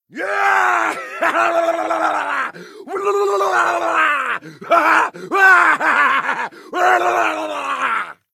laughter_07